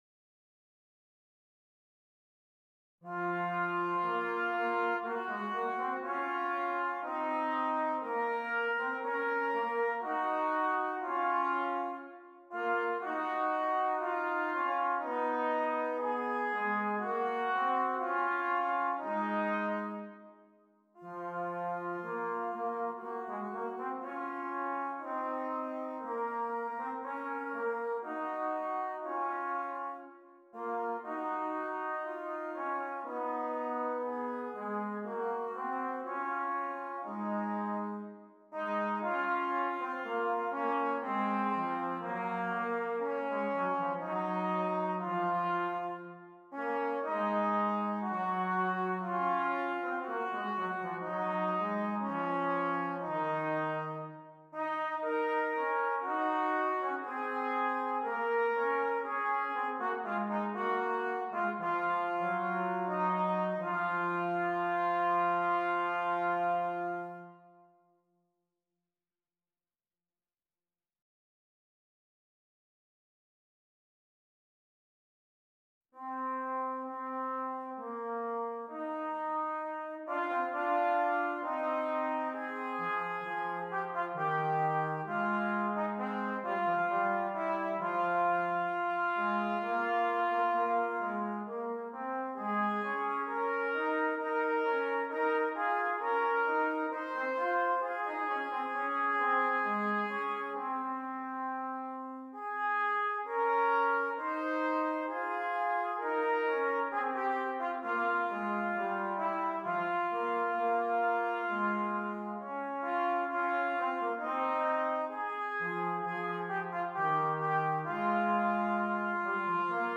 Brass Band
Trumpet, Horn or Trumpet, Trombone